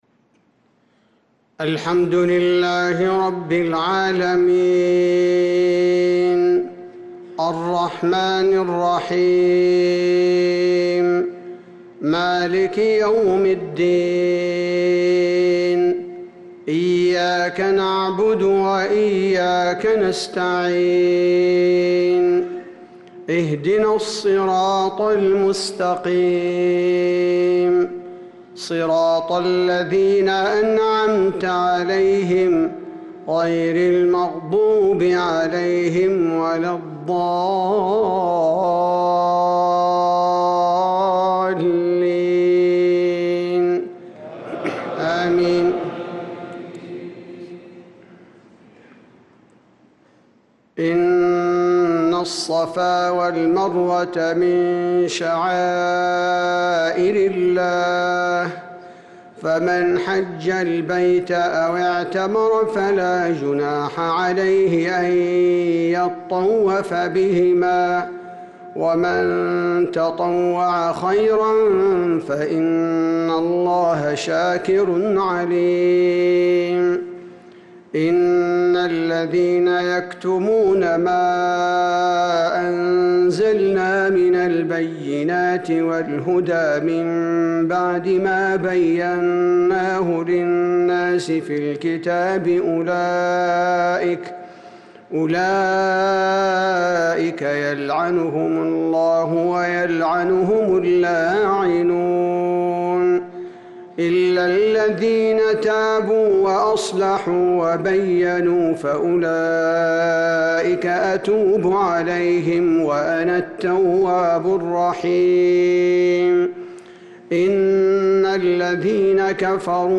صلاة المغرب للقارئ عبدالباري الثبيتي 6 ذو القعدة 1445 هـ
تِلَاوَات الْحَرَمَيْن .